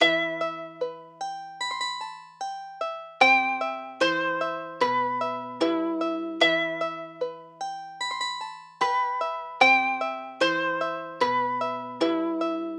描述：E小调的原声吉他合成器。
Tag: 150 bpm Trap Loops Synth Loops 2.15 MB wav Key : Em Ableton Live